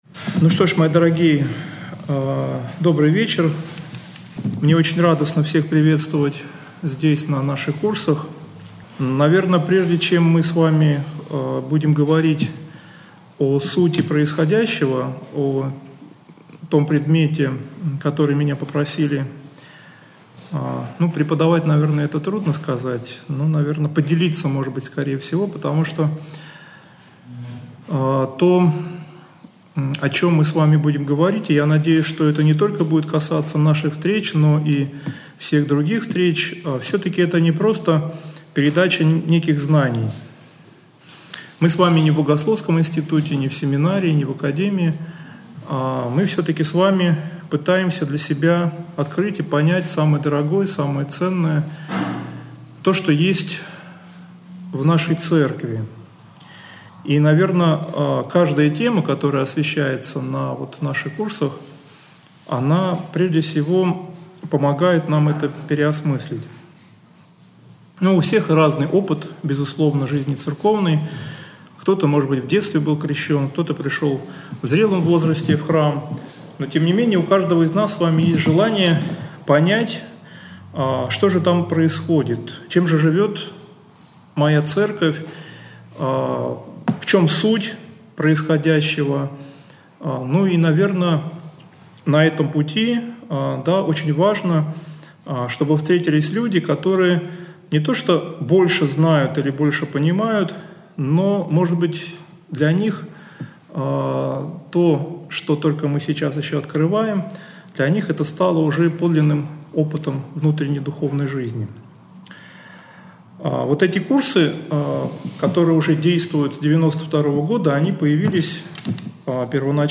лекции
Общедоступный православный лекторий 2013-2014